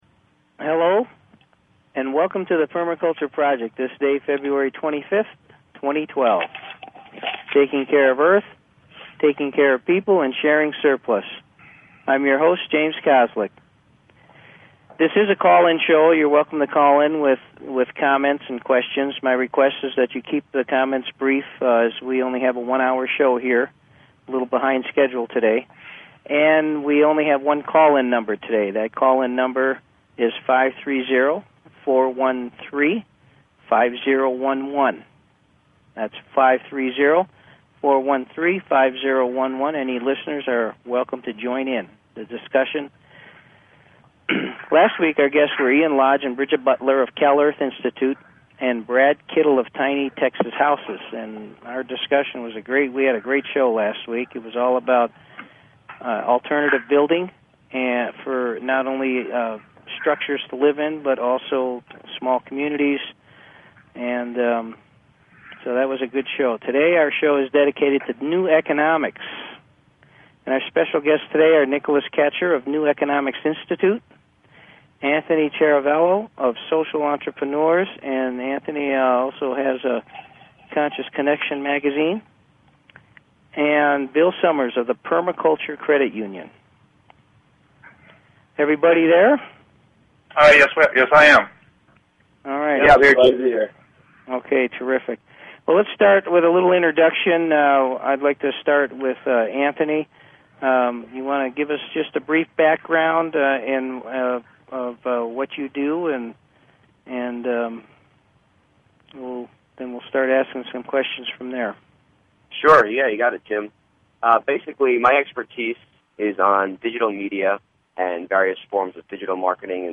Talk Show Episode, Audio Podcast, Permaculture_Project and Courtesy of BBS Radio on , show guests , about , categorized as